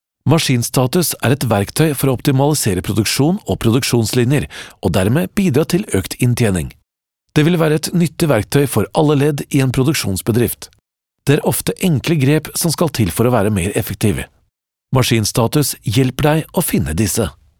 movie deep male experience voice commercial tv radio studio language norwegish norwegian trustworthy
Sprechprobe: eLearning (Muttersprache):